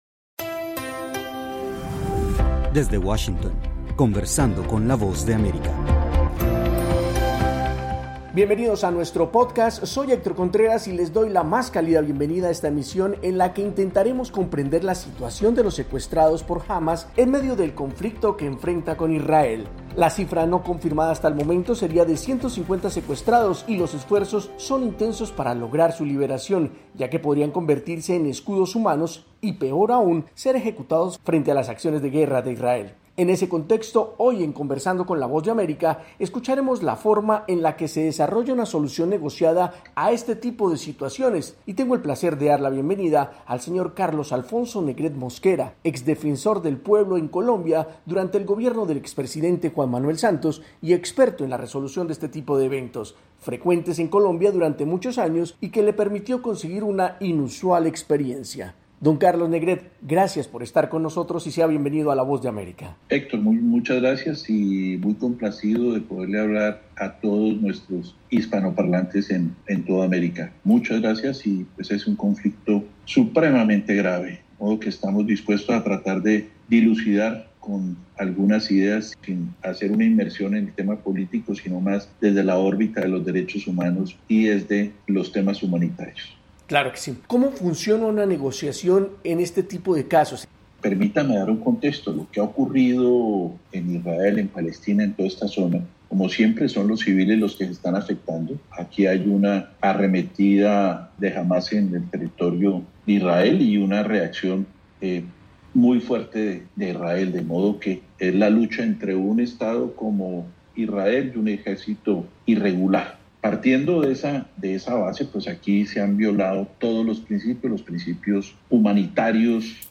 Entrevista a Carlos Negret, ex Defensor del Pueblo de Colombia y experto en negociaciones de liberación de rehenes.